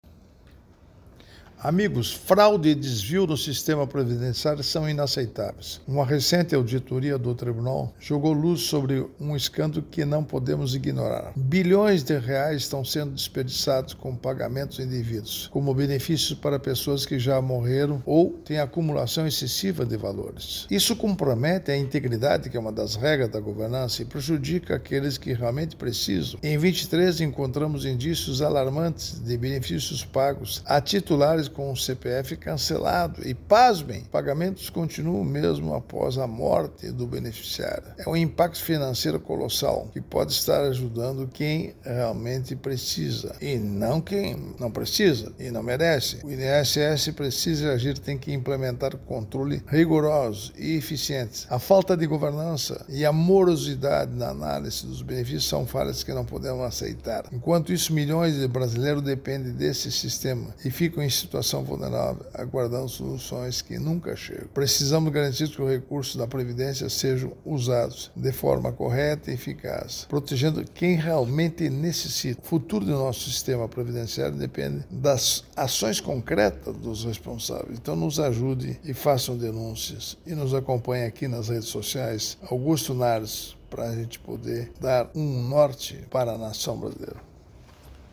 Comentário de Augusto Nardes, ministro do Tribunal de Contas da União.